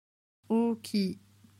Ecoutez comment on dit certains mots de l’histoire en japonais: